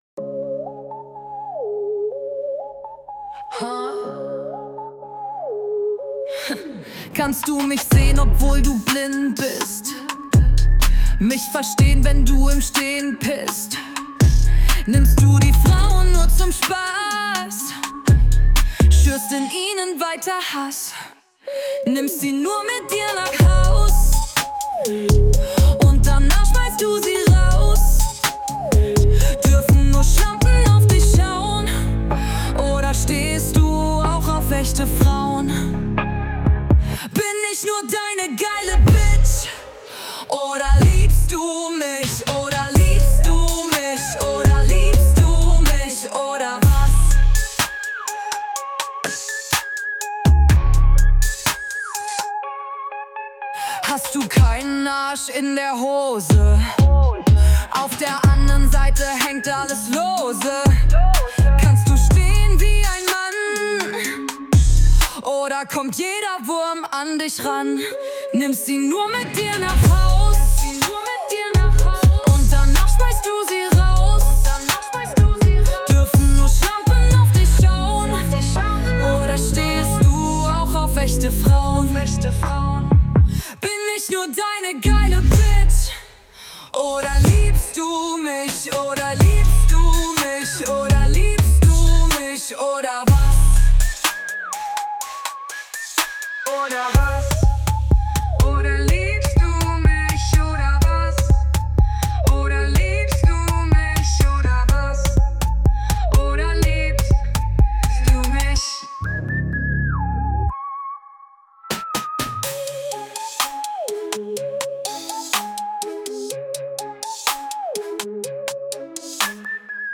Genre:                  DeutschRap